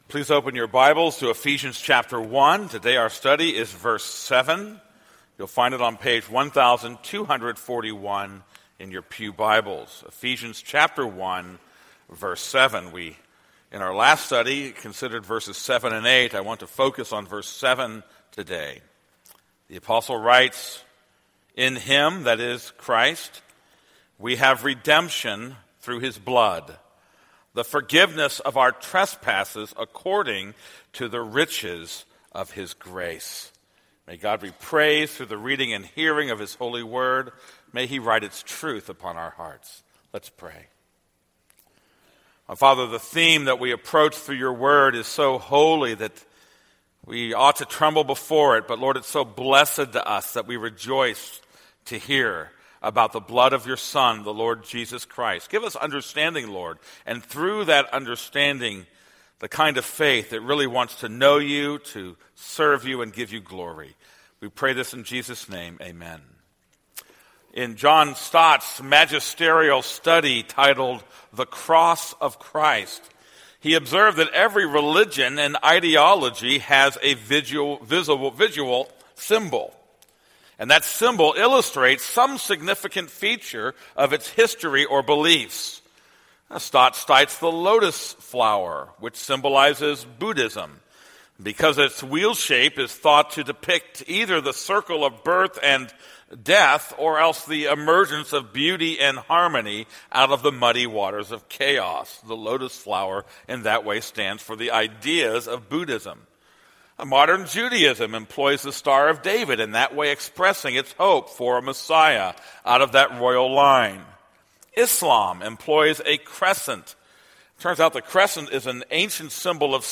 This is a sermon on Ephesians 1:7.